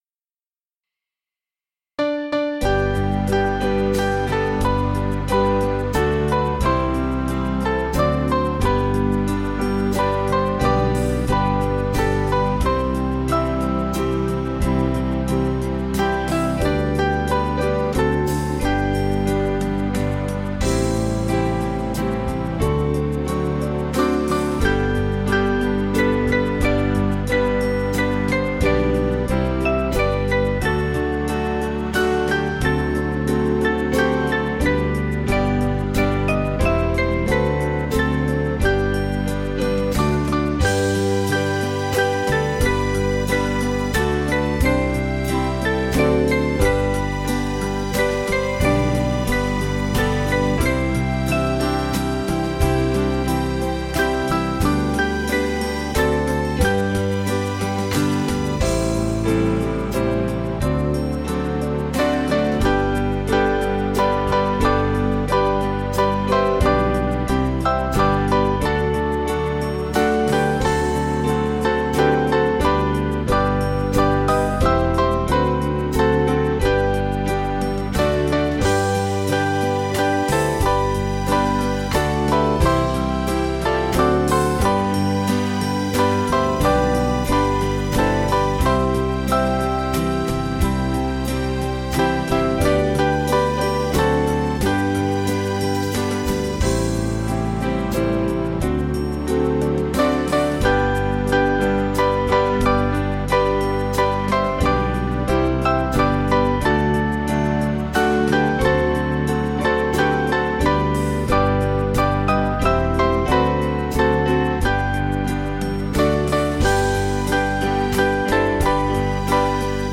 Band MP3